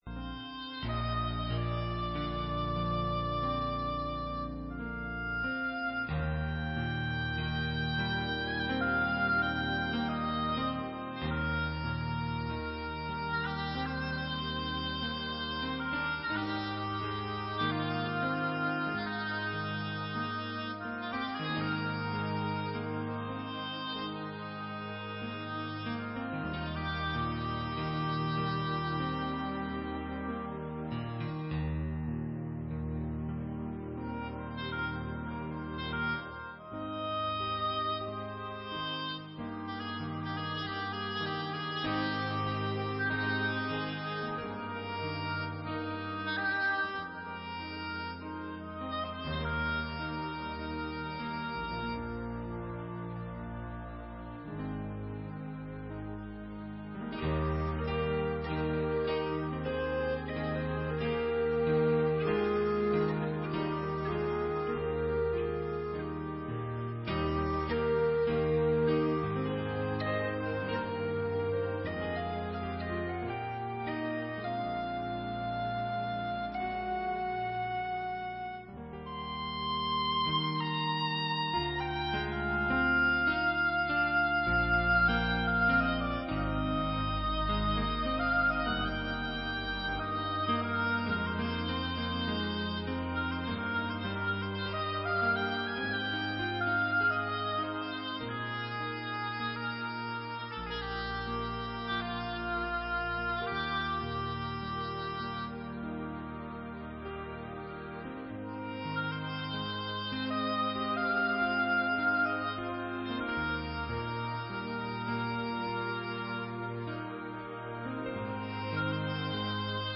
Panel: Templo de la Precipitación by 2024 Serapis Bey - Los hijos del UNO